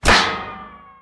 auto_hit_altmetal1.wav